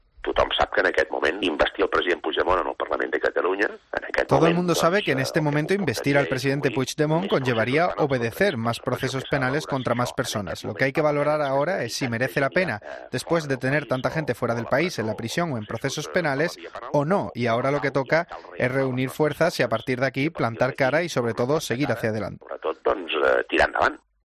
En una entrevista a Rac-1, Artur Mas ha indicado que hay que analizar si vale la pena intentar ahora la investidura de Puigdemont, en un momento en el que varios dirigentes independentistas están encarcelados o fuera de España, o es mejor "rearmarse" y formar gobierno para "plantar cara y tirar hacia adelante".